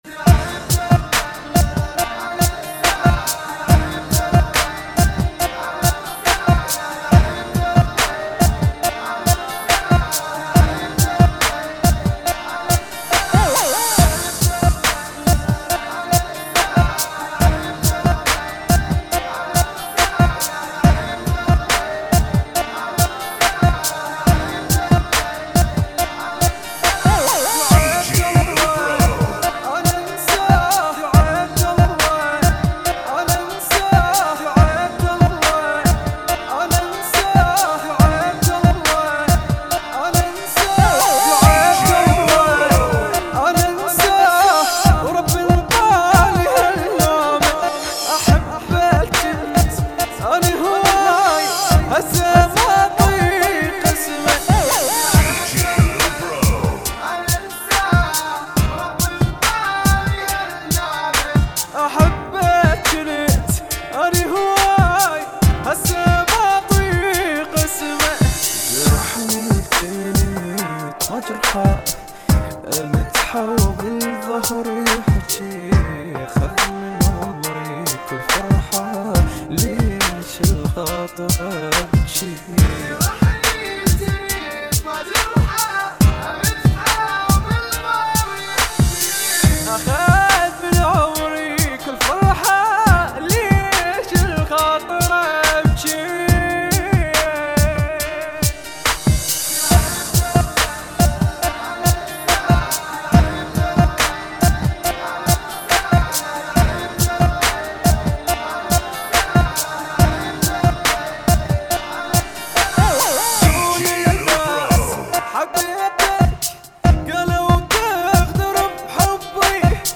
[ 70 bpm ] FunKy